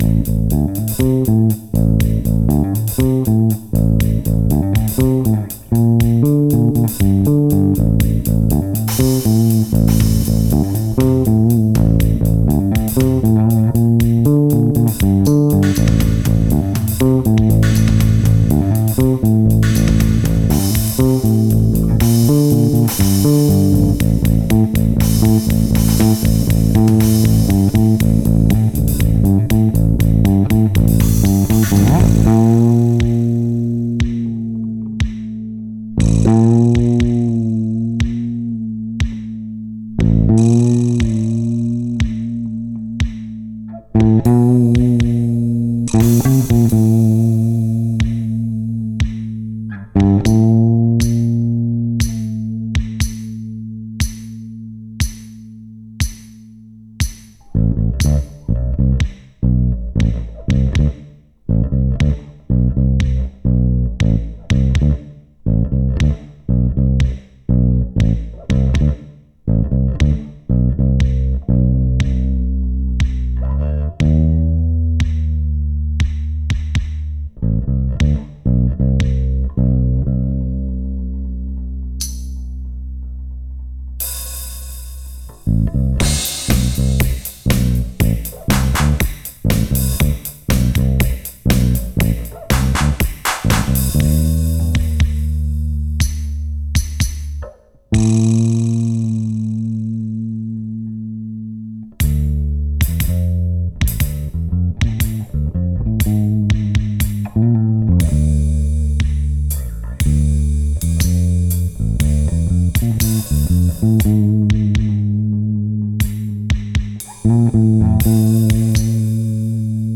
• Жанр: Фолк
инструментальная композиция